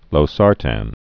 (lō-särtăn)